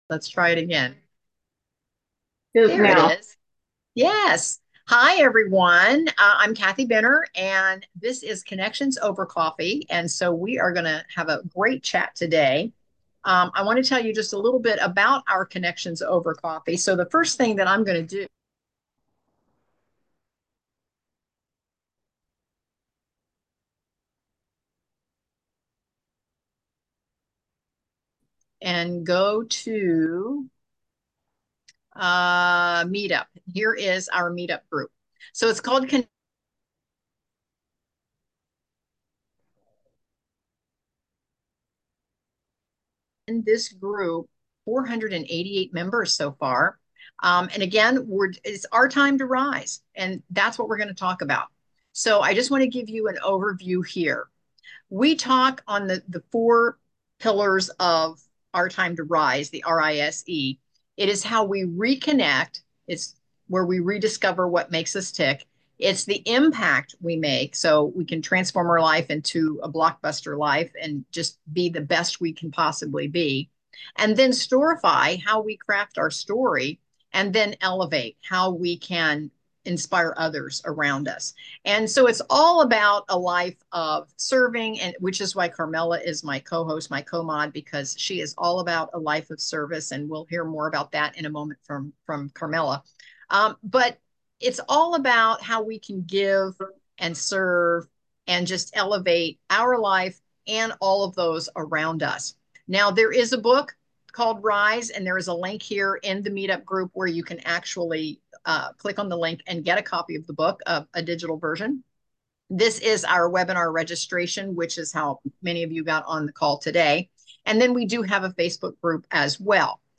Your story is more powerful than you realize. In this conversation, we explore how your journey—struggles, and successes—shapes who you are today and creates a deep connection with others.